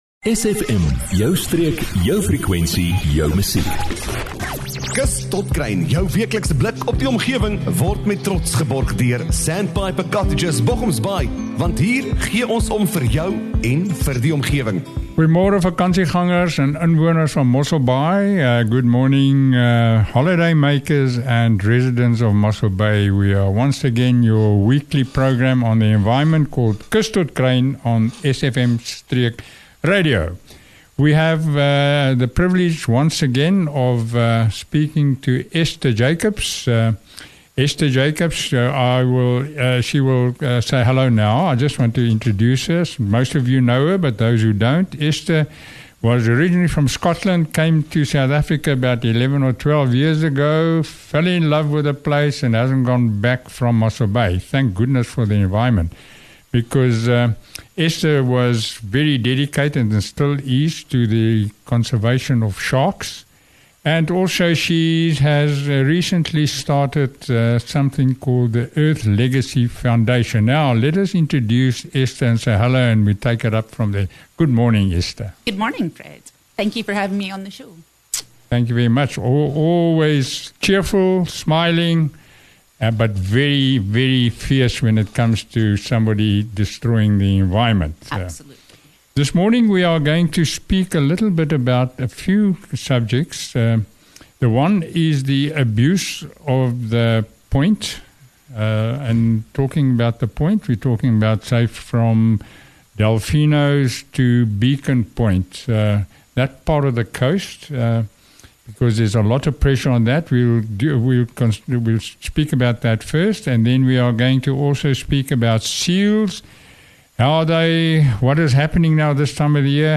🐢🦭 Ons bespreek kwessies soos aasversameling, robbababas en skilpaaie wat op die strande beland – en wat ons kan doen om ons kosbare seediere te beskerm. 💙 📻Luister hierdie belangrike gesprek weer: